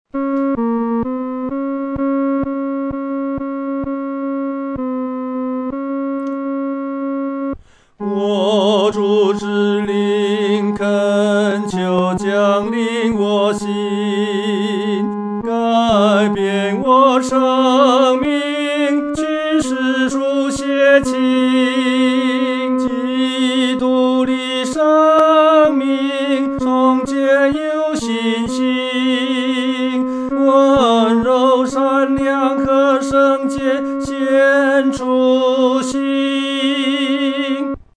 独唱（第三声）
我主之灵恳求降临-独唱（第三声）.mp3